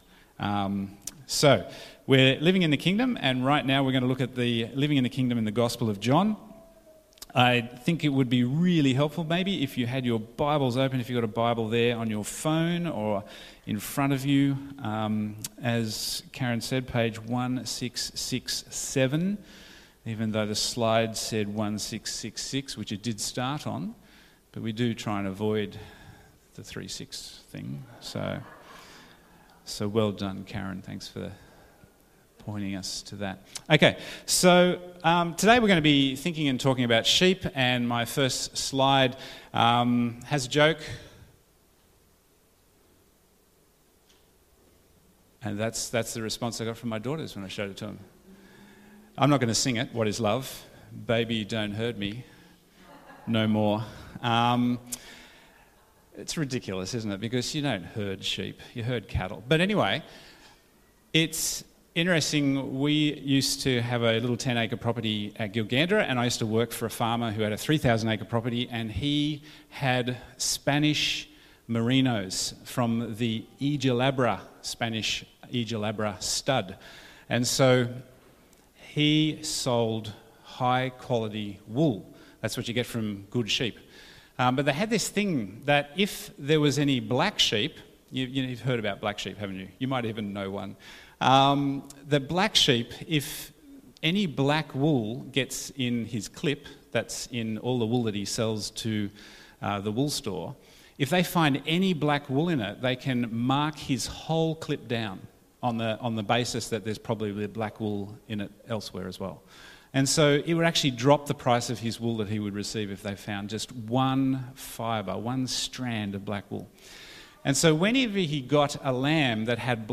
Bible Text: John 10:11-21 | Preacher